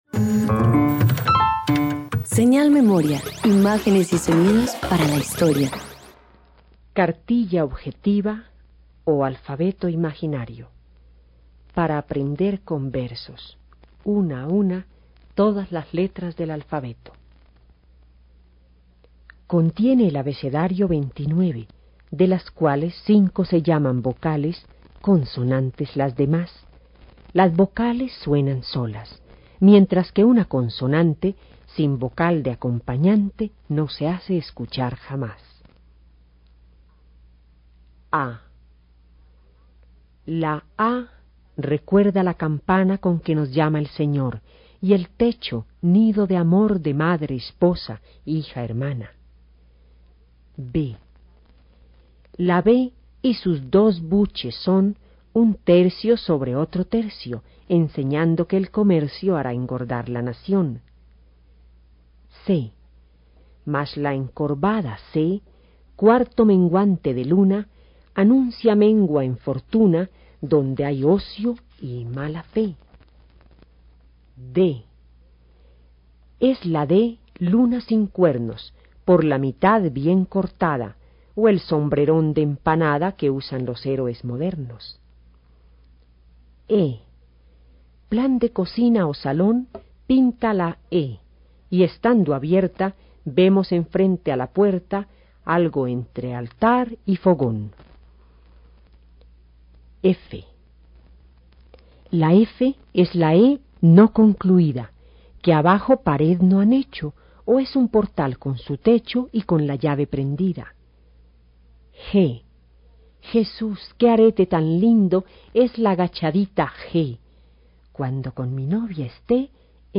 Versión sonora de la Cartilla objetiva o alfabeto imaginario para aprender con versos una a una todas las letras del alfabeto, del escritor Rafael Pombo (1833-1912). Se trata de una descripción en verso de las formas que tienen las letras del abecedario, con analogías propias de la literatura y la sociedad del siglo XIX, muy a tono con los escritos para niños con los cuales la posteridad ha recordado la obra de este poeta bogotano.